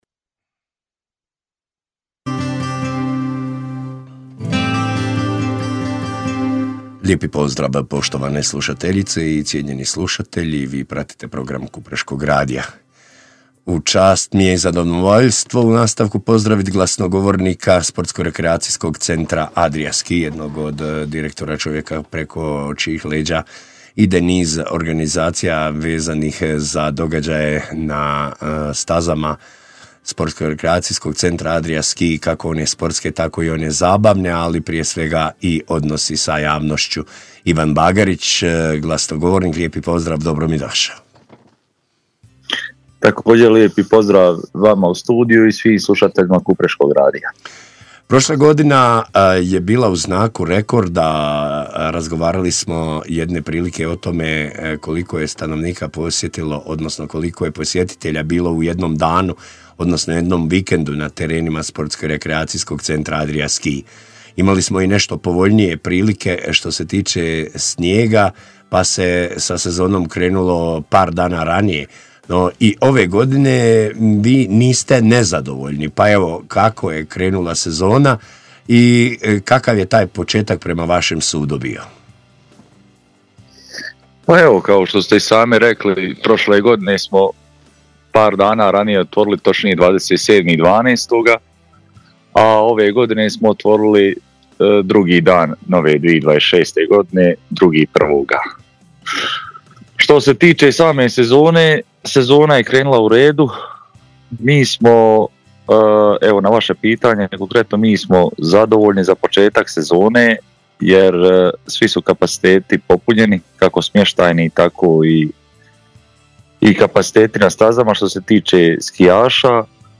za Kupreški radio